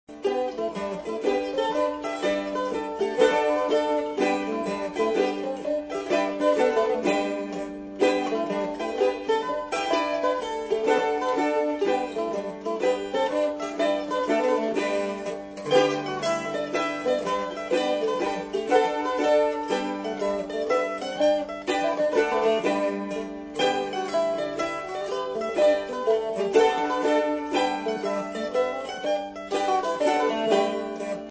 Lead Mandolin
2nd Mandolin
Octave Mandolin